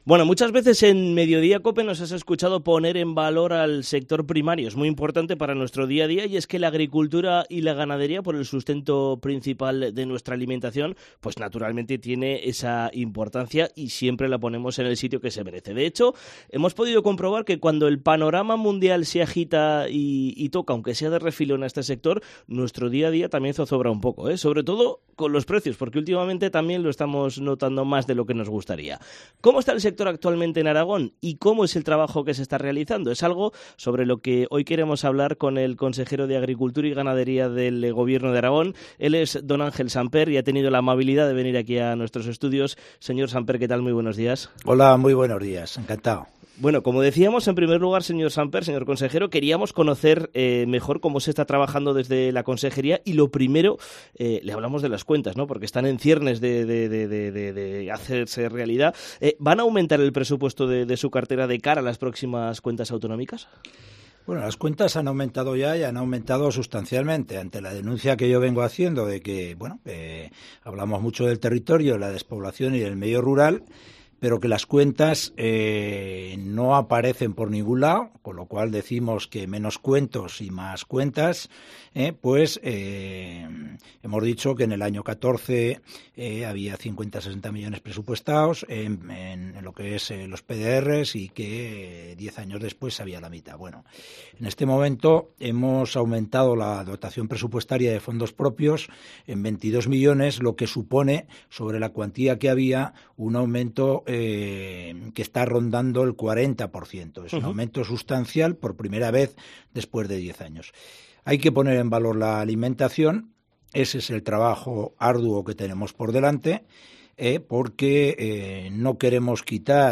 Entrevista a Ángel Samper, consejero de Agricultura y Ganadería del Gobierno de Aragón